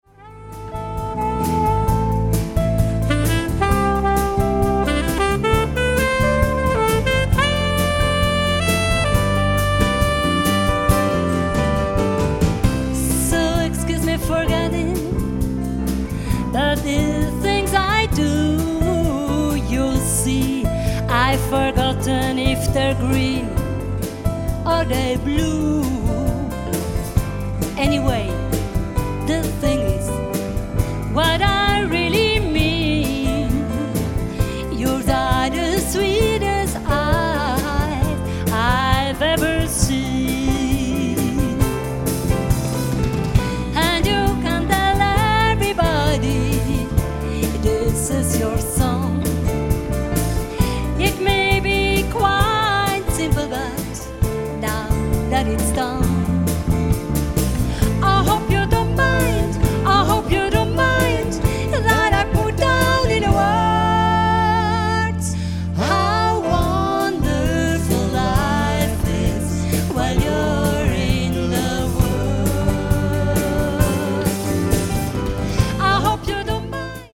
Jubiläumskonzert